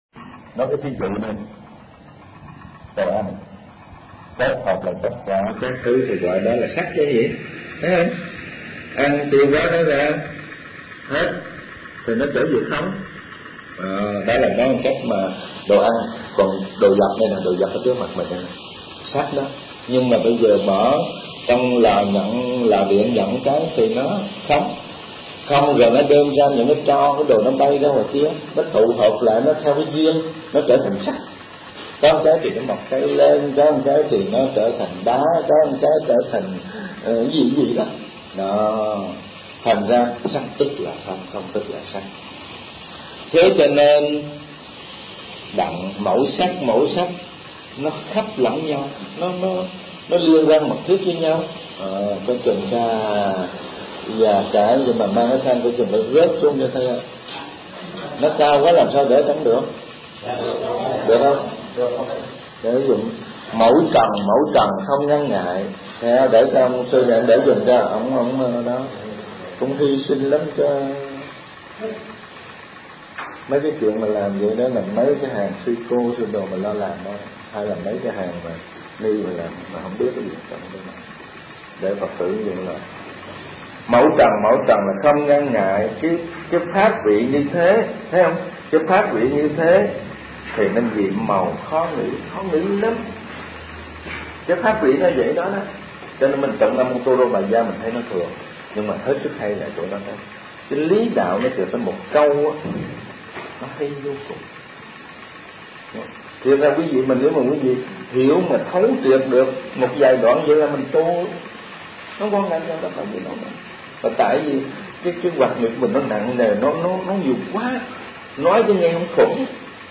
Kinh Giảng Nhị Khóa Hiệp Giải